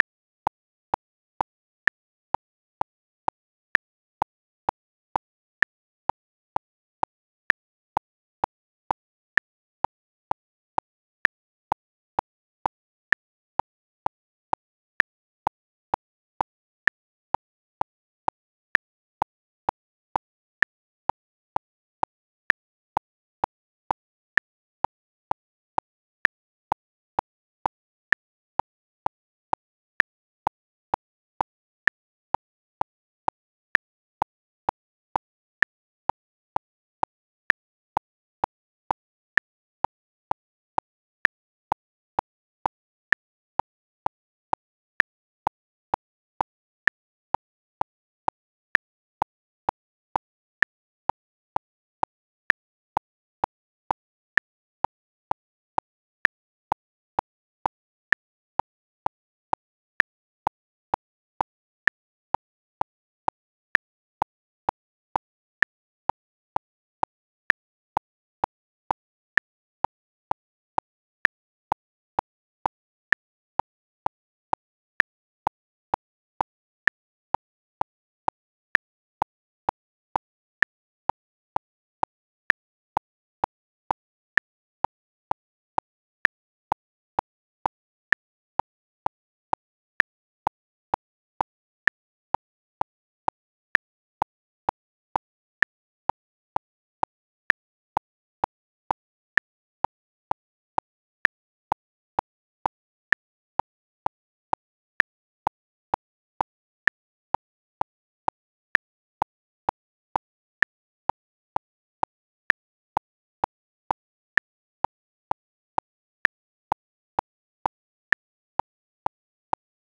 Click source-glued.wav